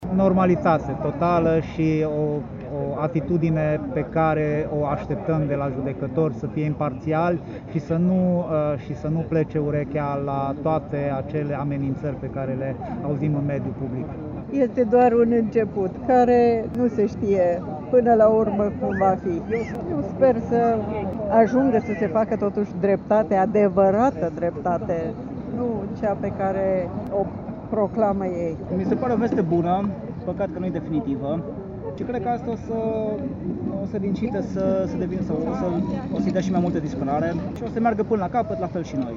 Protestul a început după ora 20:00 când în Piața Victoriei se aflau aproximativ 200 de persoane, pentru ca după ora 21:00 numărul lor să crească la circa 1.000. Oamenii au venit din nou cu steaguri tricolore și ale Uniunii Europene.
01-voxuri-Dragnea-21.mp3